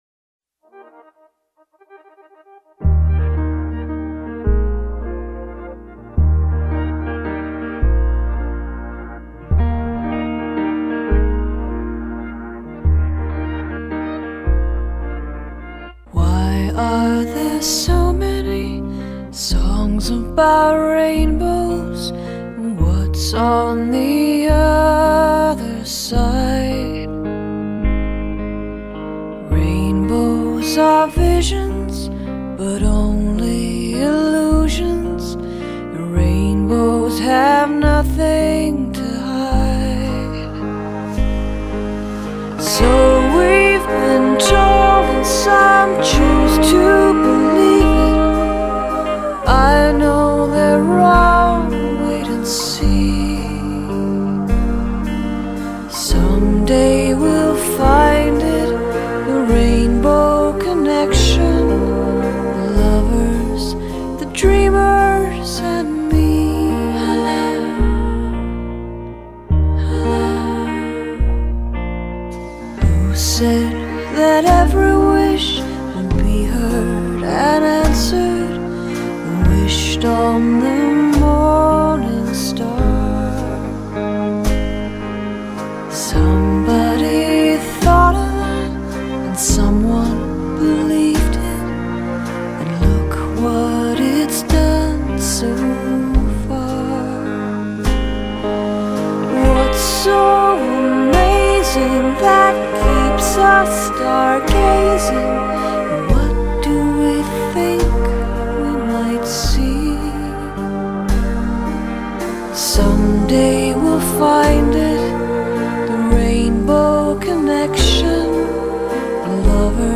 Tags: Sentimental Classic All time favorite Sweet Passionate